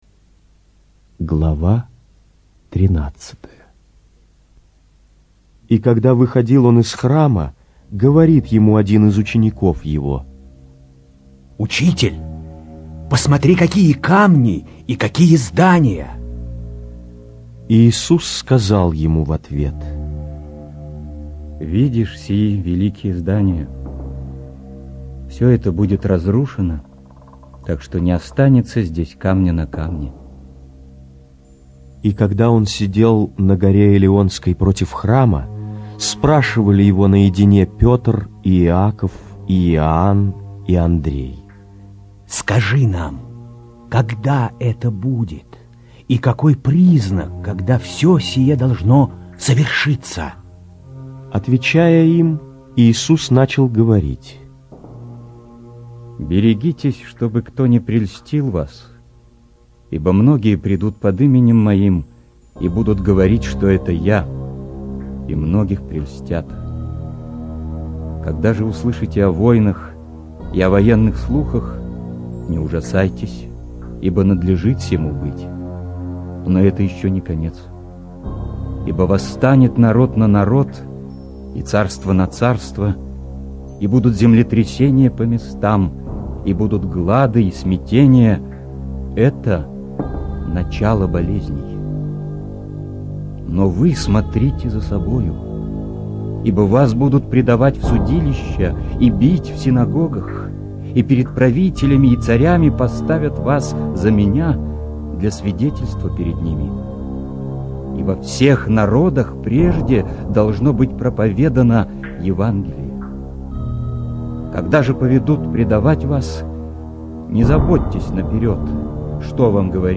инсценированная аудиозапись    Подробнее...